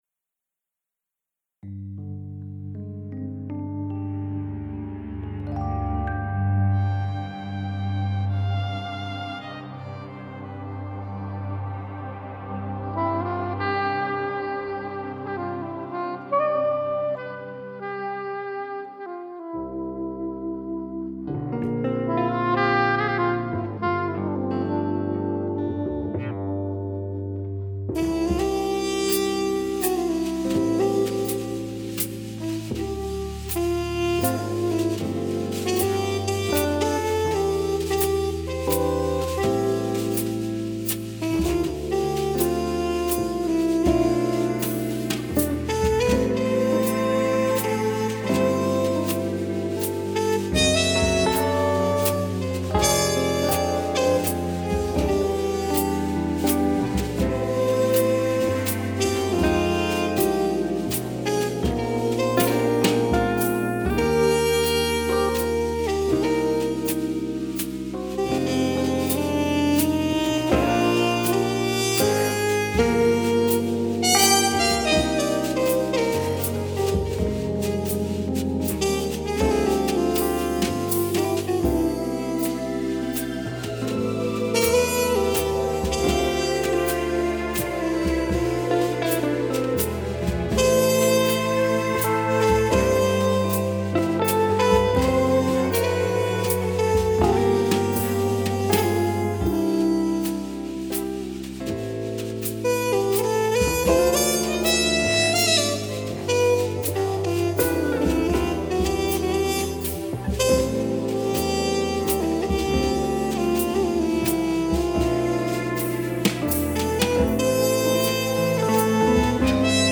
au piano
au bugle